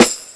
012_Lo-Fi Big Perc.1.L.wav